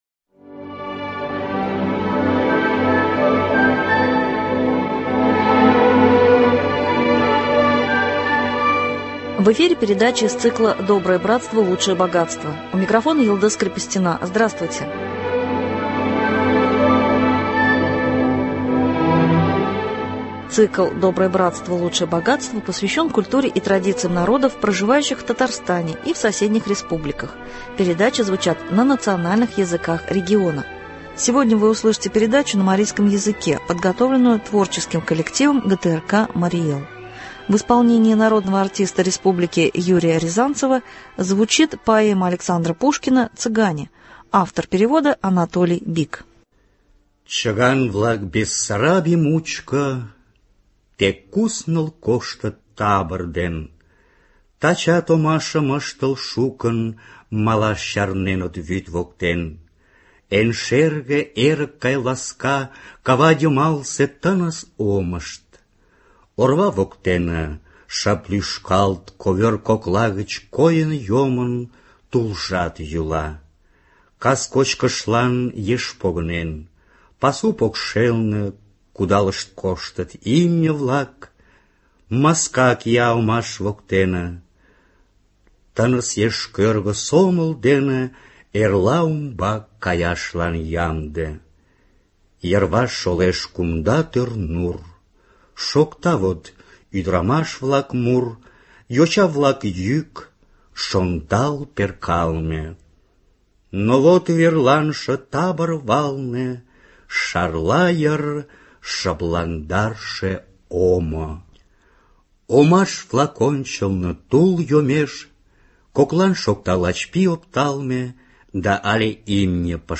Сегодня вы услышите передачу на марийском языке, подготовленную творческим коллективом ГТРК Марий Эл. В исполнении народного артиста республики